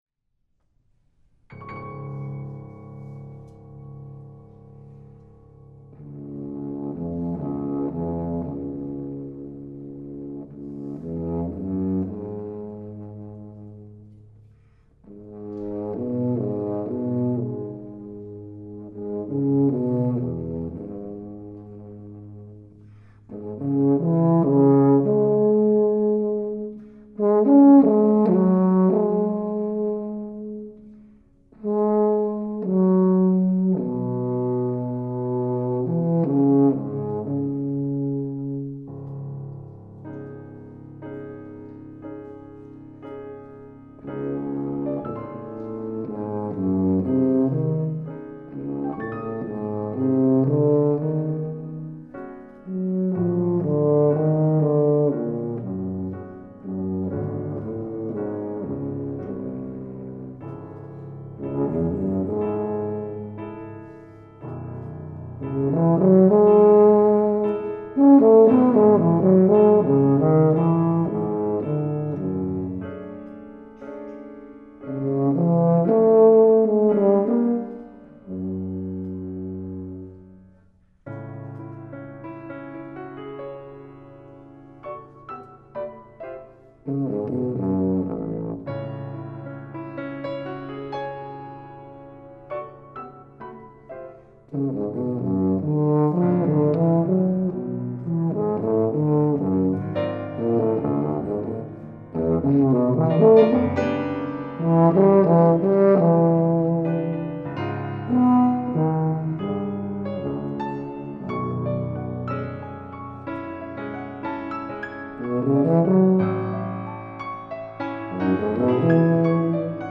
Faculty Recital 4-5-2012
Sonata for Tuba and Piano, Opus 704 by Carson Cooman